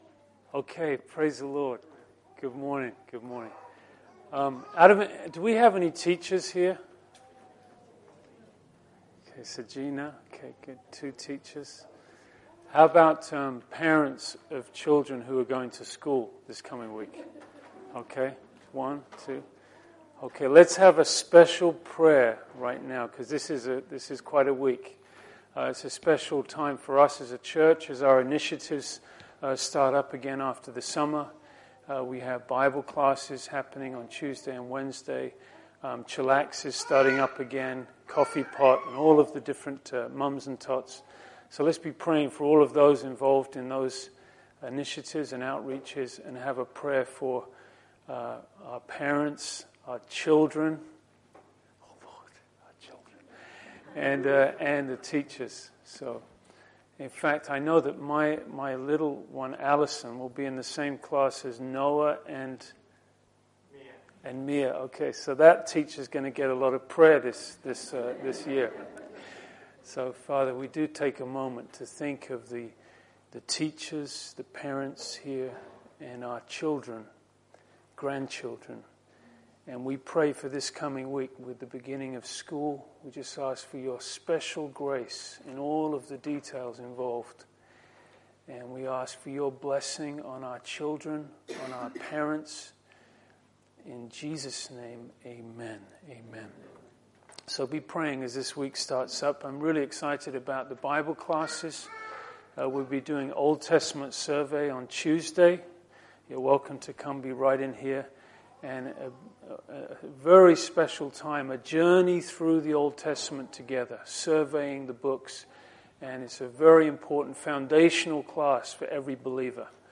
When Paul requested for the thorn to be removed, God answered with the timeless words “My Grace is Sufficient”. In this sermon we explore the sufficiency of his grace in our own lives and trials.